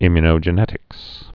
(ĭmyə-nō-jə-nĕtĭks)